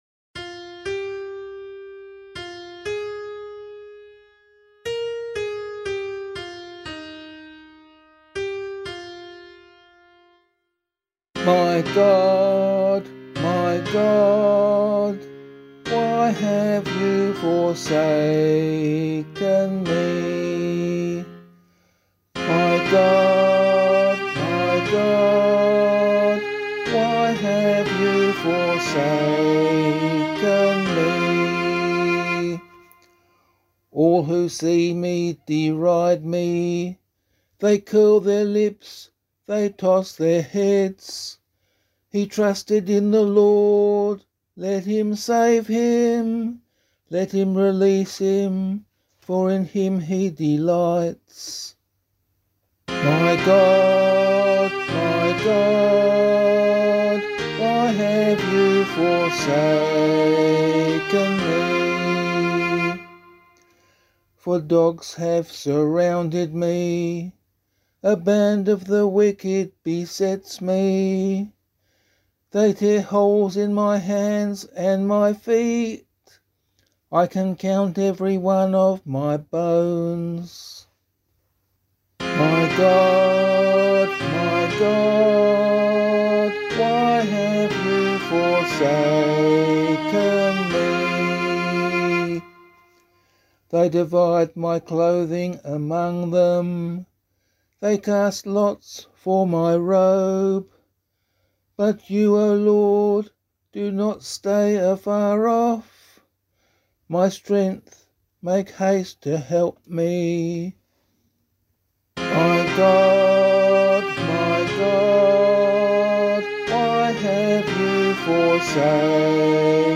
018 Palm Sunday Psalm [APC - LiturgyShare + Meinrad 2] - vocal.mp3